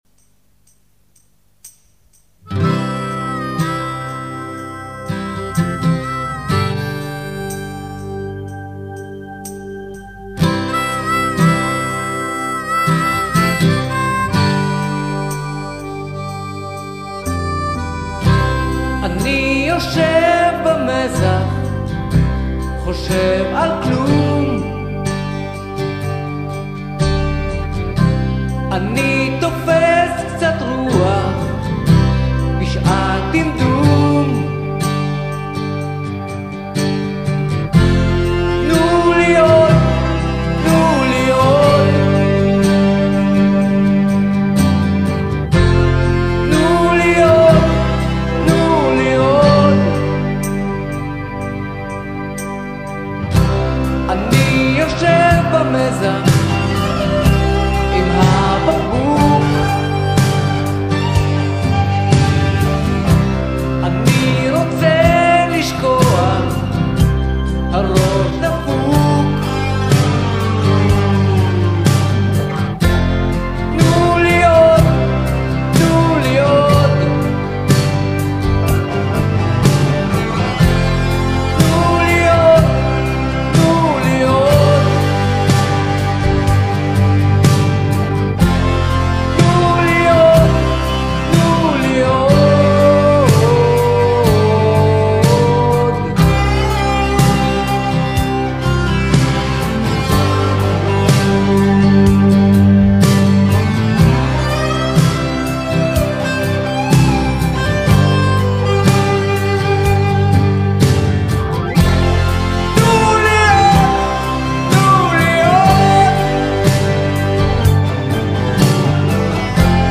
בלוז על המזח מהימים שבהם נבנה שובר הגלים הראשון מול חוף שרתון.
blues_on_the_dock.mp3